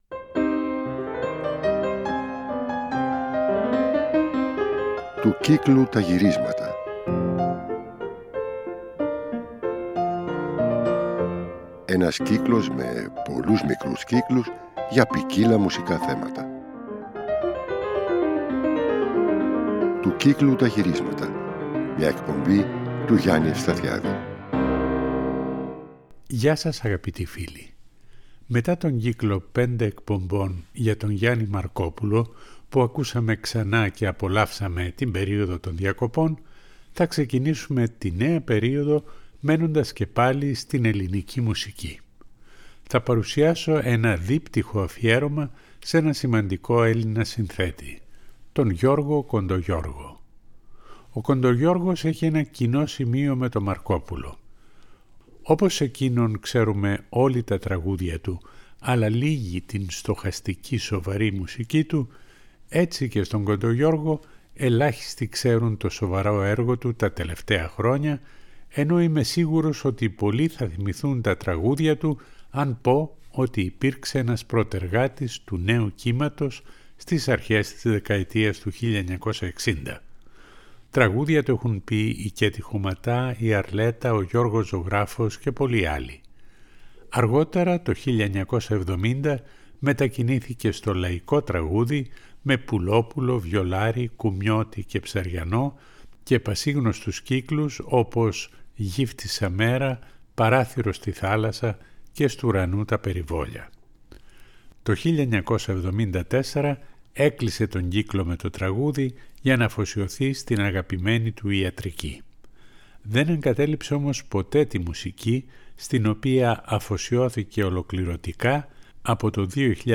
Στις δύο αυτές εκπομπές θα ακούσουμε συνθέσεις του για κιθάρα, κουαρτέτα εγχόρδων, συνθέσεις για σαξόφωνο και πιάνο και τραγουδιστικούς κύκλους.